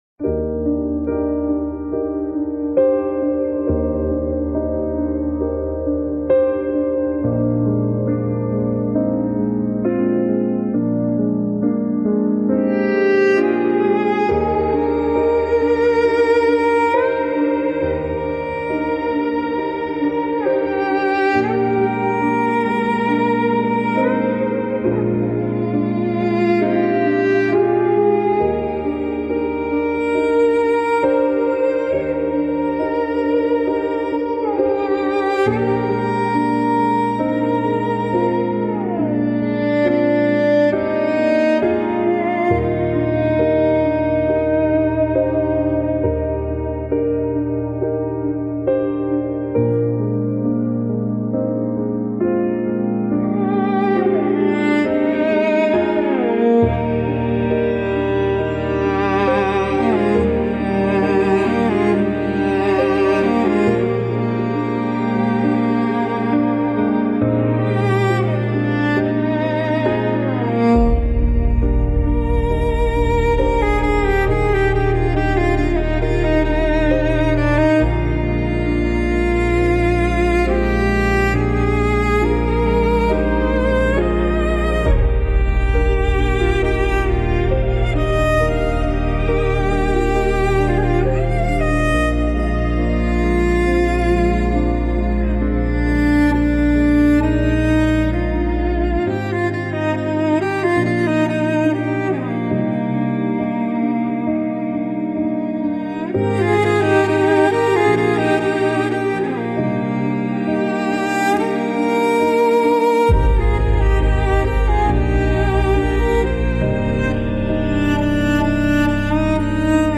Cinematic Deeply Emotive Instrumental Music
Genres: Background Music
Tempo: Medium